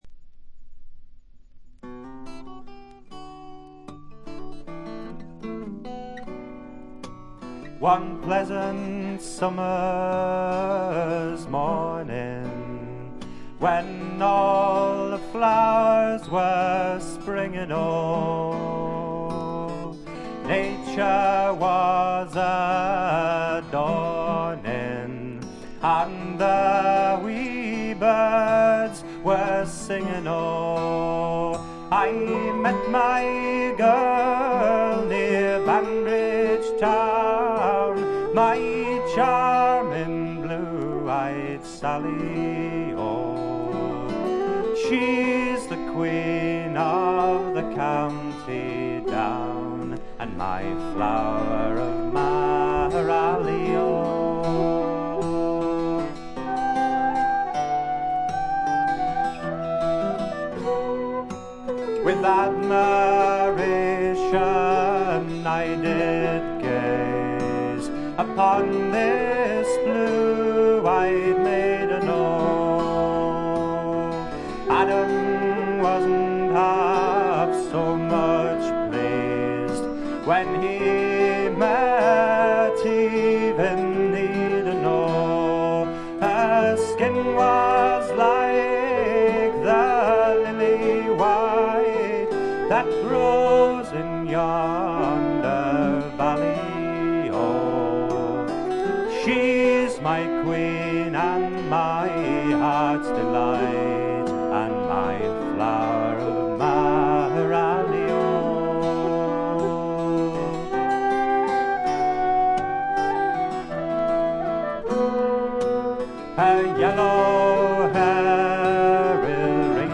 ごくわずかなノイズ感のみ。
アコースティック楽器のみで、純度の高い美しい演奏を聴かせてくれる名作です。
試聴曲は現品からの取り込み音源です。